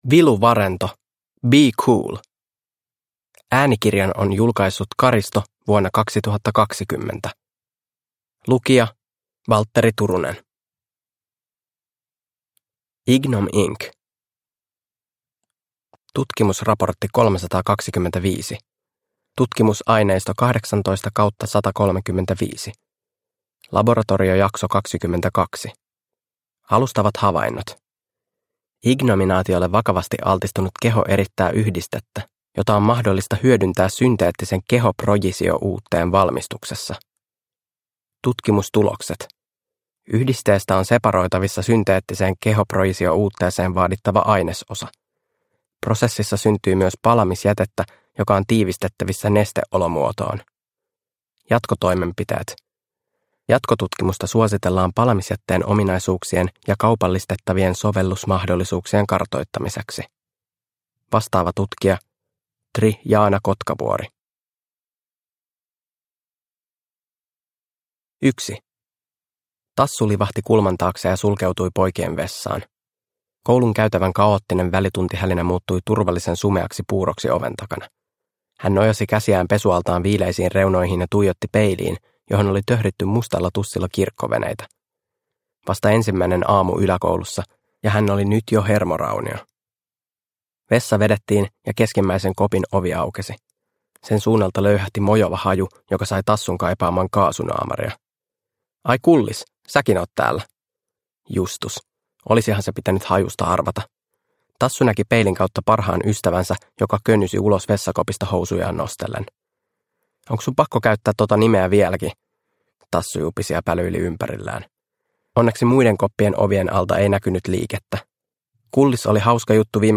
Be Cool – Ljudbok – Laddas ner